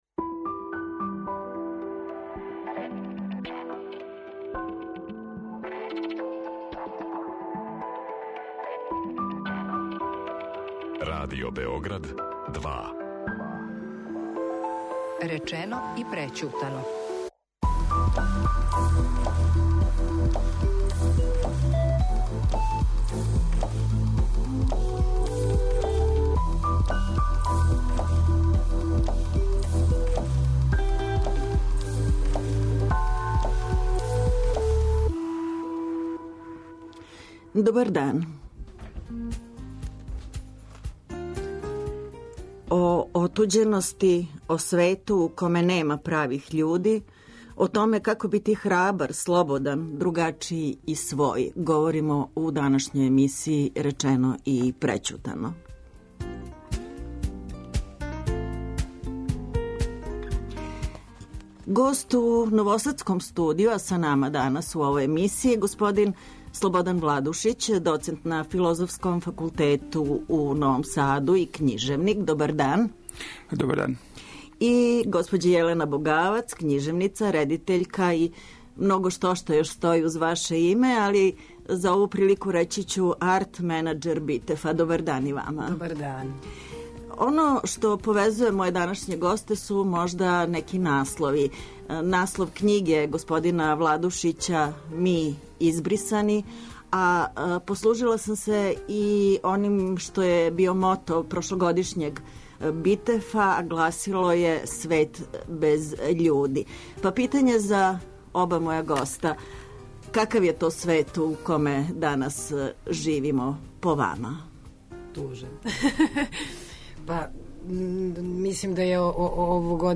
Гост у новосадском студију